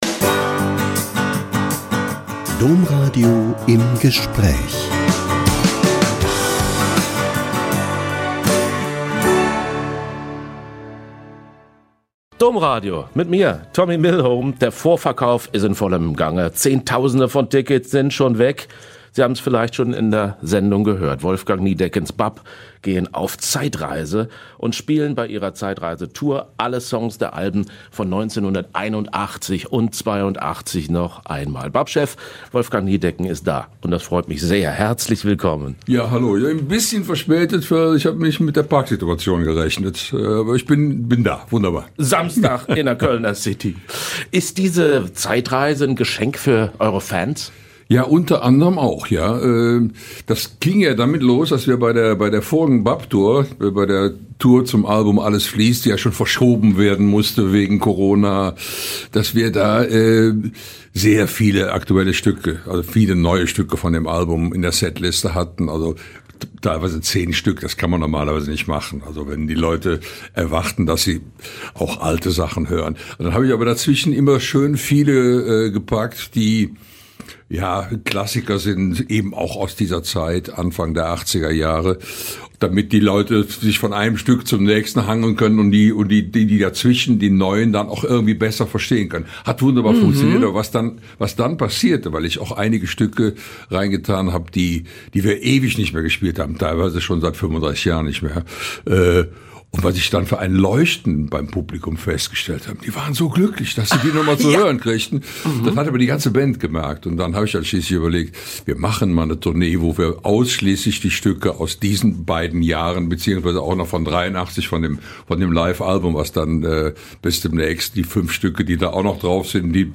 Wolfgang Niedecken spricht über Glauben und alte BAP-Songs - Ein Interview mit Wolfgang Niedecken (Sänger der Kölsch-Rock-Gruppe BAP) # Menschen und Musik ~ Im Gespräch Podcast